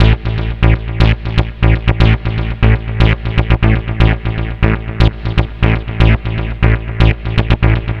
TSNRG2 Bassline 029.wav